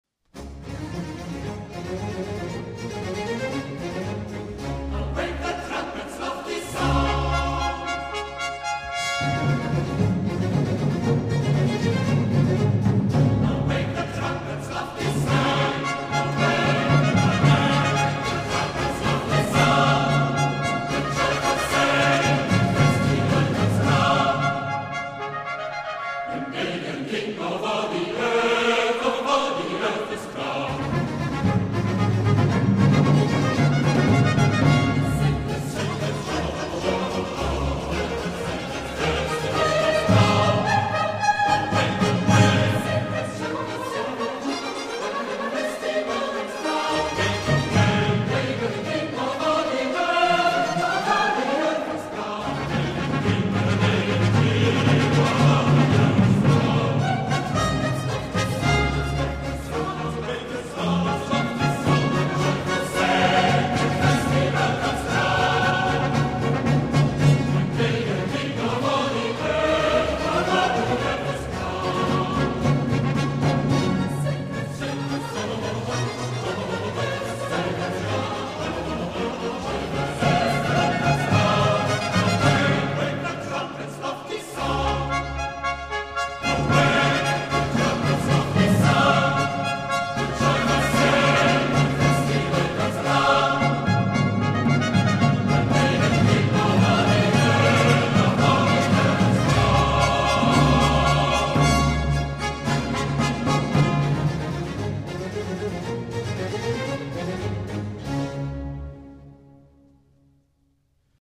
Er legt vor allem Wert darauf, so viel wie möglich von der ganz besonderen Aura der Konzerte zu vermitteln, was ausgezeichnet gelingt.
(Mezzosoprano)
Arioso from Jephtha ~ CD II, No. 5